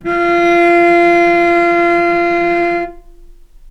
healing-soundscapes/Sound Banks/HSS_OP_Pack/Strings/cello/ord/vc-F4-mf.AIF at a9e67f78423e021ad120367b292ef116f2e4de49
vc-F4-mf.AIF